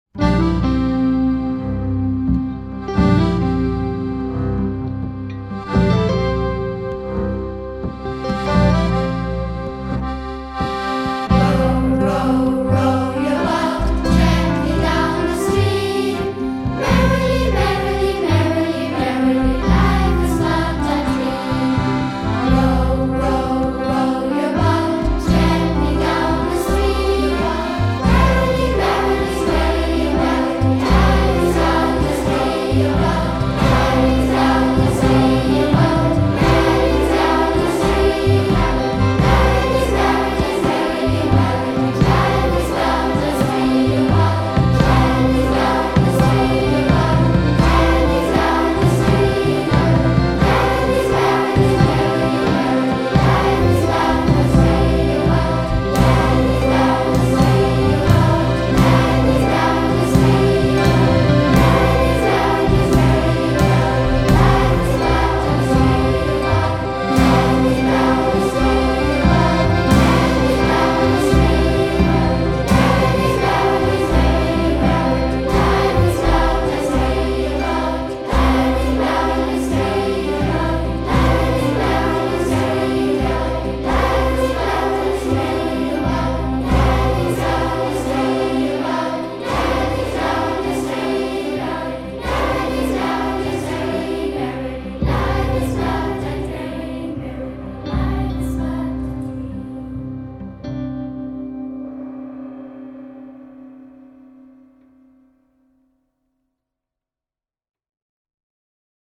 Row your Boat - Einspielung als Kanon